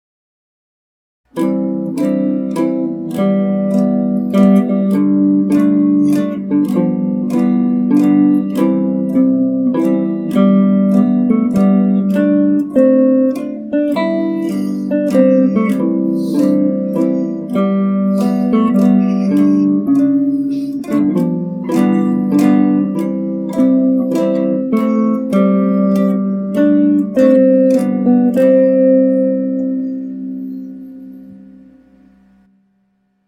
So for the interim I pulled up an online virtual keyboard to work out the notes and write them down, then later, when it was late enough, got to the real keyboard and worked out the chords that went with it.
It’s a short little thing, only 16 bars long, in ¾ time, but I think it sounds kind of nice.